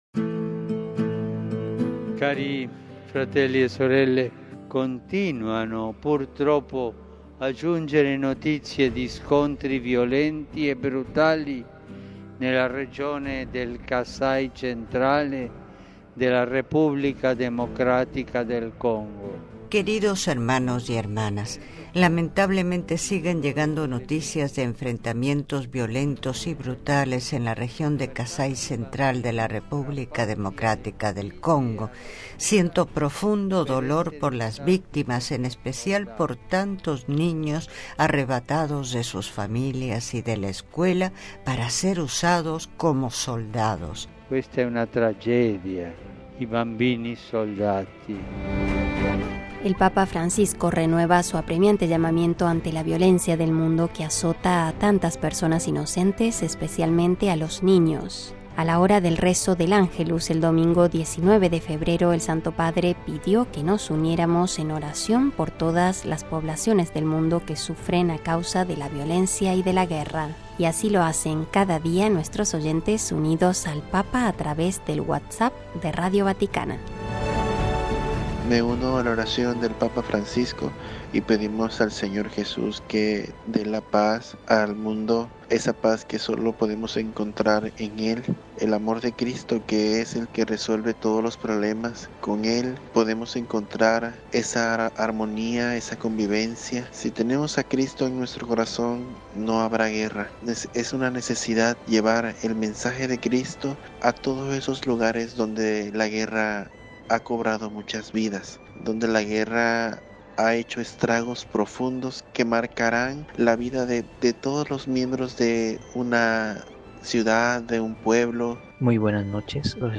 Los oyentes de Radio Vaticana no permanecen indiferentes ante estas palabras de Francisco y a través de nuestro número de WhatsApp, unen sus voces reflexionando y orando por la grave situación de violencia en el mundo, que en sus múltiples formas, va progresivamente aumentando y en consecuencia, acabando con la armonía de los pueblos.
Una vez más, agradecemos la participación de nuestra audiencia en el programa Tu Comentario Ayuda de Radio Vaticana, que cada día envía sus mensajes de AUDIO a nuestro WhatsApp, con los que elaboramos contenidos en «diálogo virtual» con el Papa Francisco.